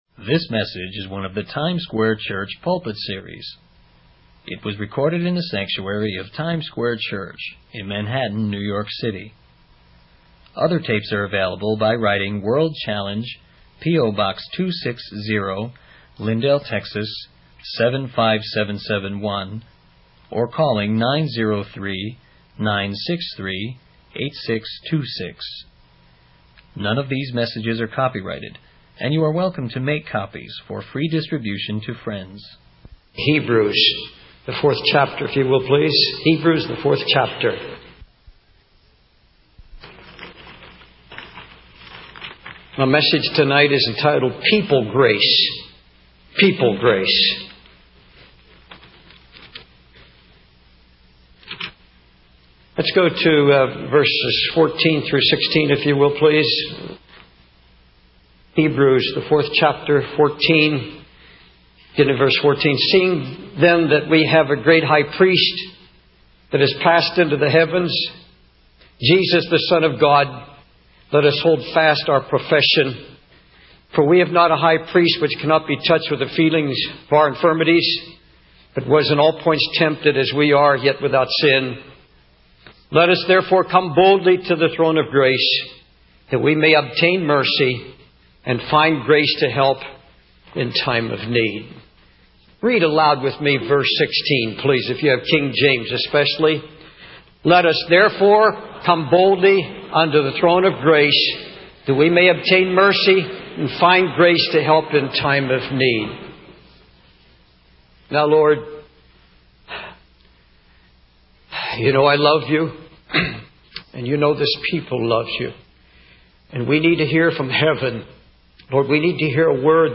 In this sermon titled 'People Grace,' the preacher addresses the topic of enduring hardships and finding comfort in God's grace. The sermon begins with a prayer for all those who are hurting and emphasizes God's love for everyone. The preacher then reads from Hebrews 4:14-16, highlighting the role of Jesus as our high priest.